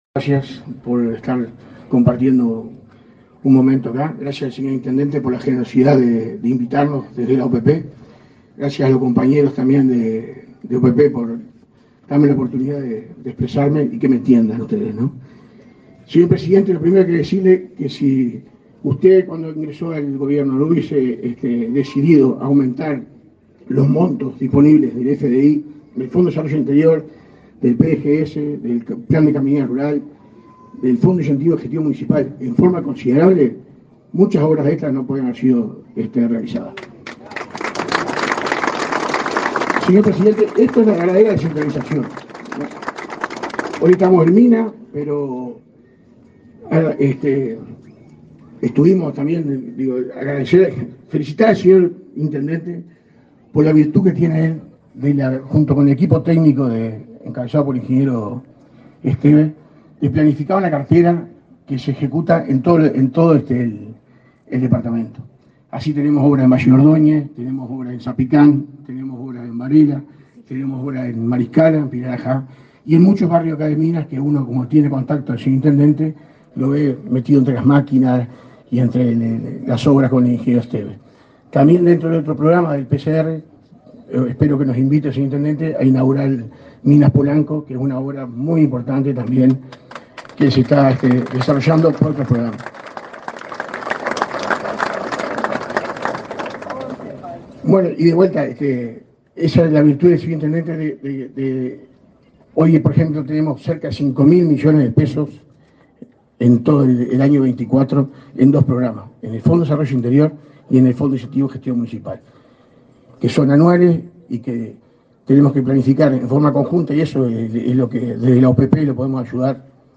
Acto por la inauguración de obras viales en Minas
Acto por la inauguración de obras viales en Minas 22/02/2024 Compartir Facebook X Copiar enlace WhatsApp LinkedIn Con la presencia del presidente de la República, Luis Lacalle Pou, fue inaugurado, este 21 de febrero, el Bulevar Luis Alberto de Herrera, en la ciudad de Minas. En el acto participaron el encargado del Fondo de Desarrollo del Interior (FDI), Antonio Barrios, y el intendente de Lavalleja, Mario Luis García.